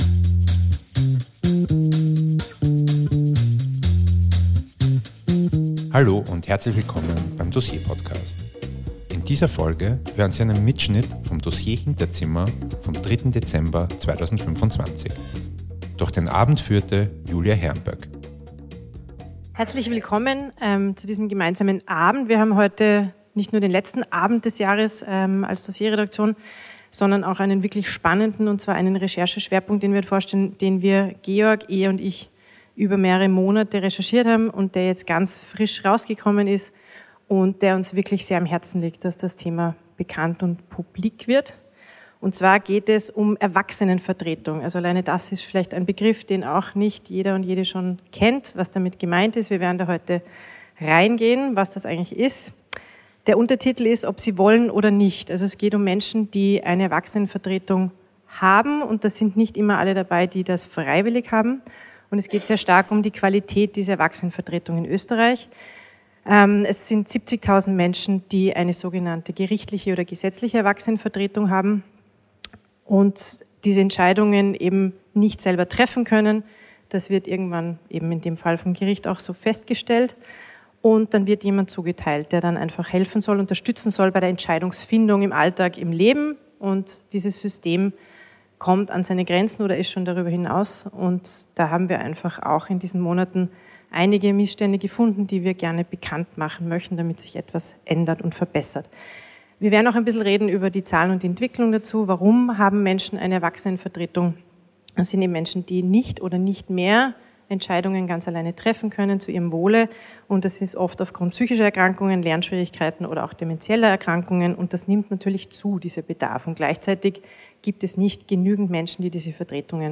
Bei unserer Recherche sind wir auf ein gravierendes Systemversagen gestoßen – genau dort, wo vulnerable Menschen Schutz brauchen. Hören Sie den Mitschnitt von der Präsentation des Magazins beim DOSSIER-Hinterzimmer am 3.12.2025 in der Roten Bar des Wiener Volkstheaters.